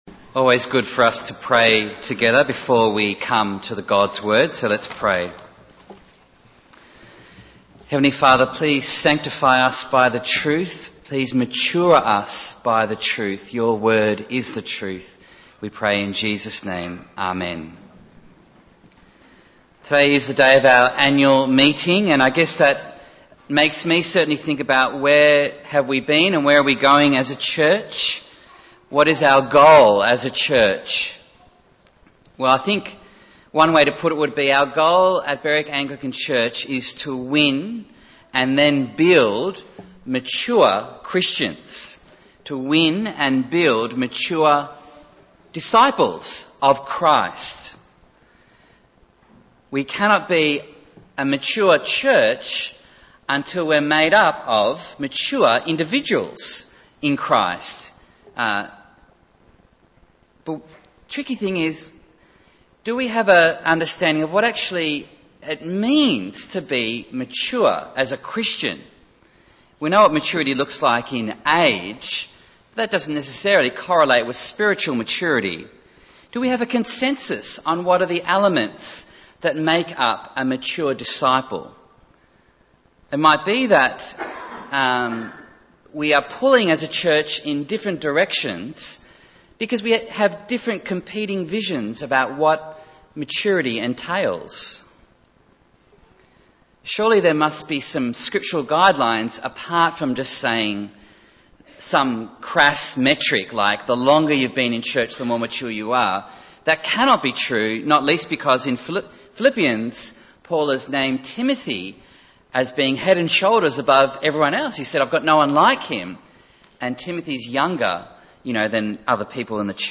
Bible Text: Philippians 3:1-16 | Preacher